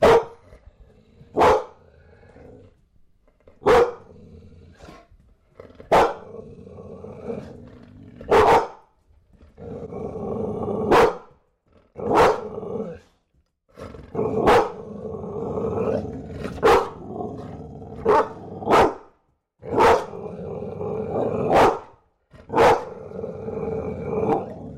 Звуки бульдога
Звук лая домашнего бульдога: злобное рычание